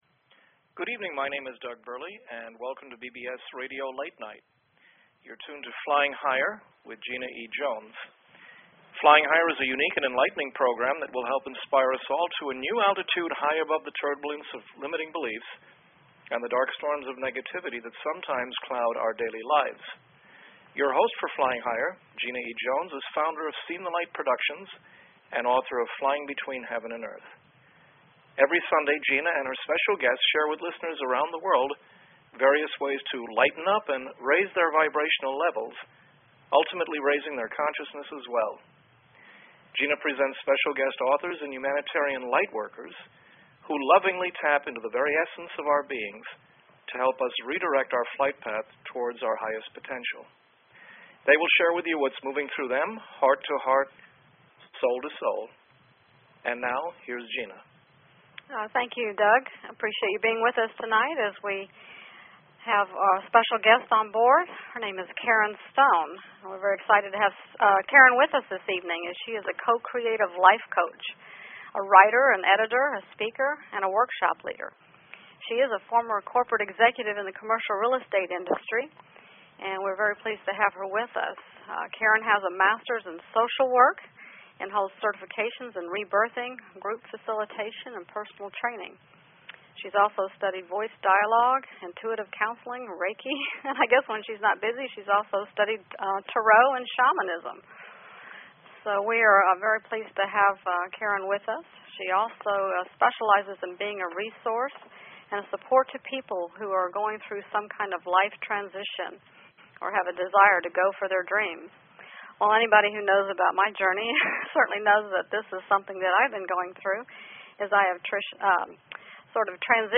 Talk Show Episode, Audio Podcast, Flying_Higher and Courtesy of BBS Radio on , show guests , about , categorized as